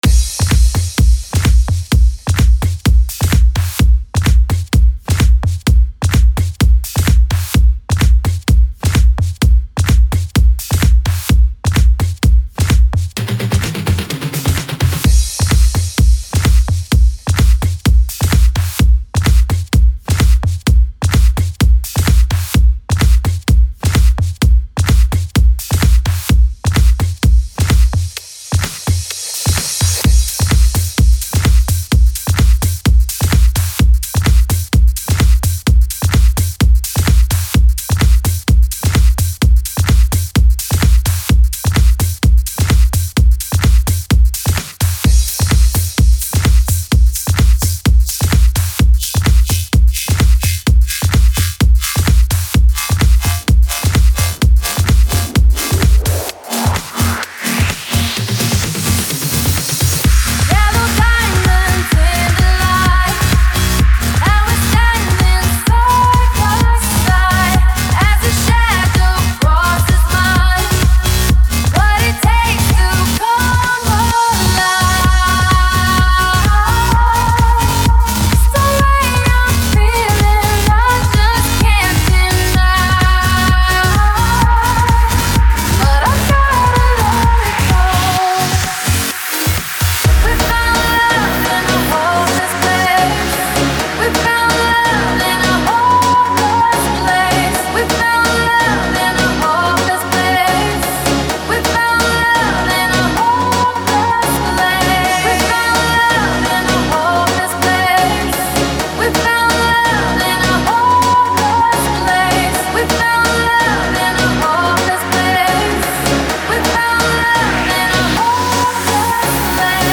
Стиль: Electro-House Год выпуска: 2012